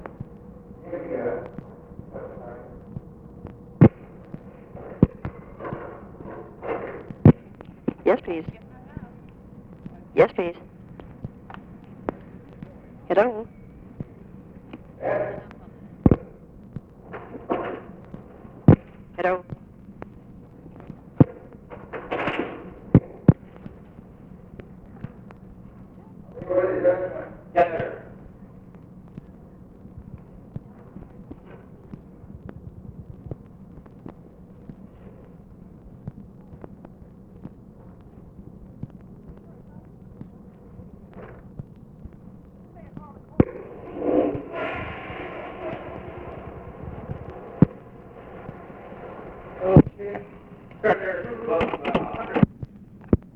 Conversation with OFFICE CONVERSATION
Secret White House Tapes | Lyndon B. Johnson Presidency